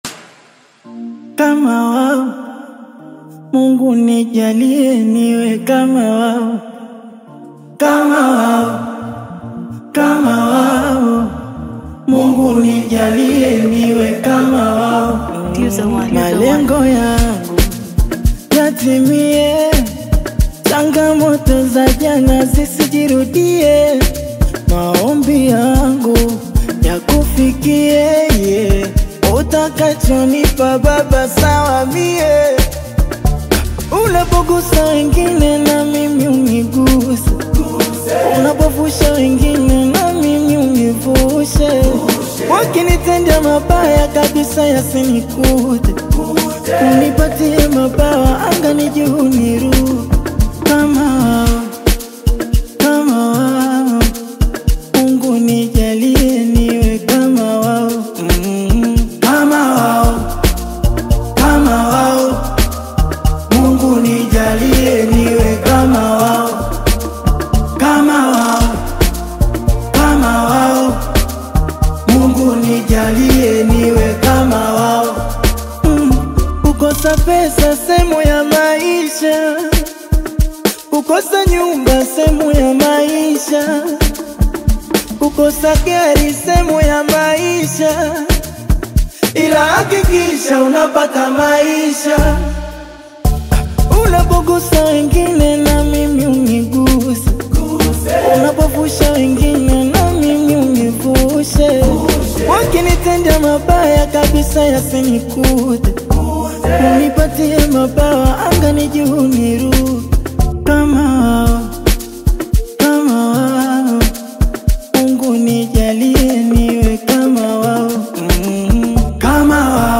Genre: Amapiano